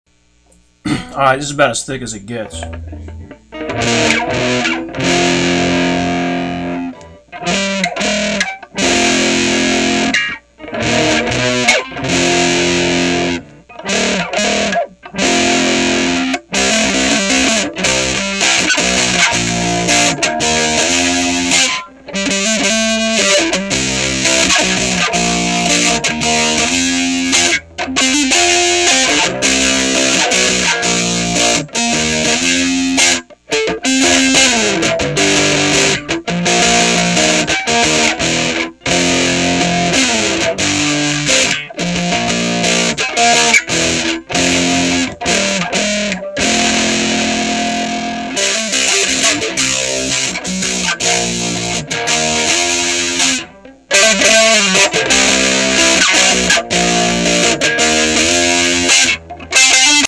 This amp is known for its thick and dirty sound.
• Raw signal recorded into a laptop with one of those headsets with a microphone on it. No compression, reverb, pedals, or talent whatsoever. What you hear is what you get!
• 1992 Strat deluxe plus, neck pickup and volume on 10
• Microphone is about 6 inches in front of speaker(s) and turned way down
• Drive and gain obviously cranked
Cut 1: Thick and Dirty (recorded before making the amp quiet)